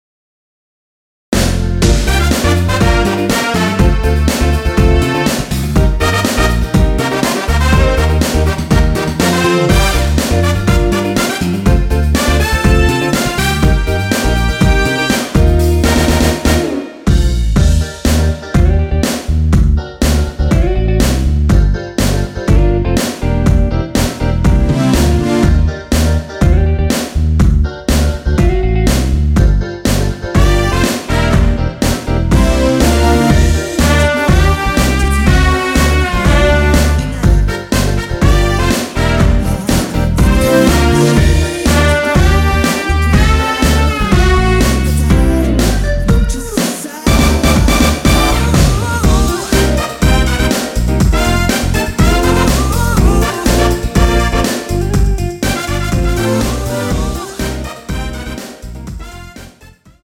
랩은 코러스가 아니라 포함되어있지 않습니다.
원키 코러스 포함된 MR입니다.
Gm
앞부분30초, 뒷부분30초씩 편집해서 올려 드리고 있습니다.